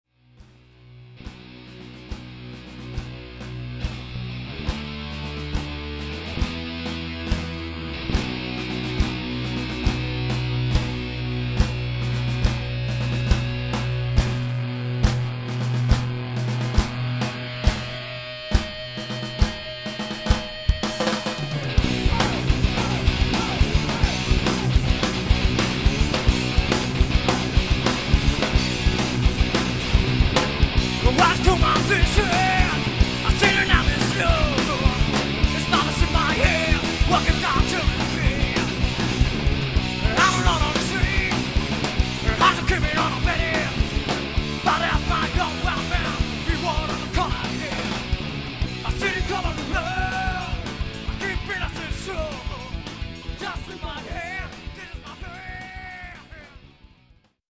Live CD